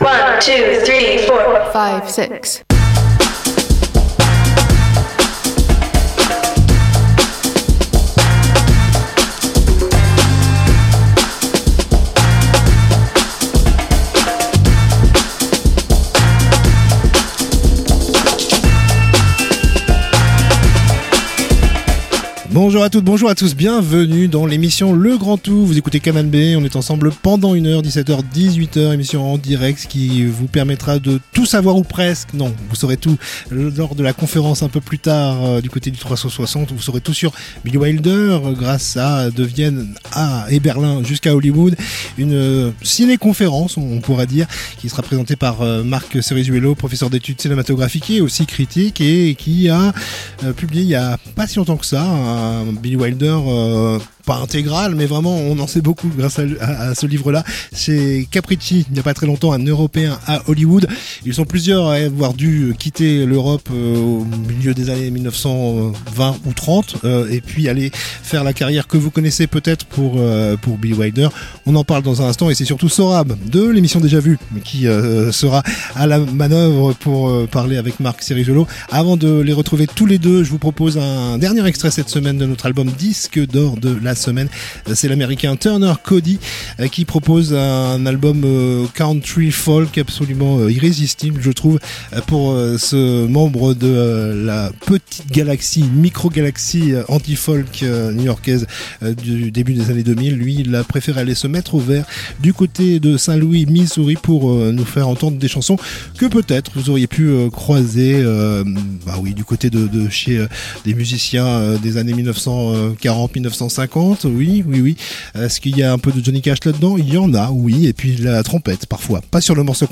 itv culture